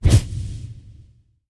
Звуки вспышки света
В коллекции представлены как резкие импульсные звуки, так и мягкие световые эффекты.
Стробоскопический световой импульс